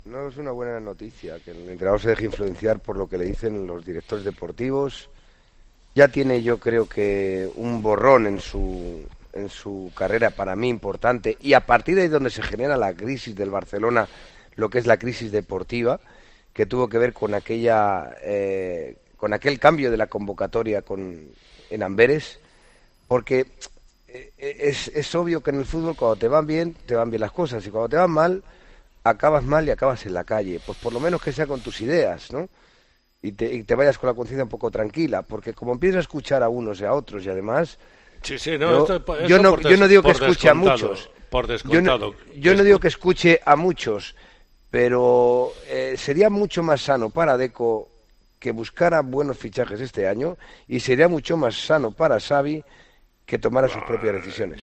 El tertuliano de Tiempo de Juego y El Partidazo ha anlizado la situación de Xavi y Deco en el Barcelona para terminar ofreciendo un consejo para la próxima temporada.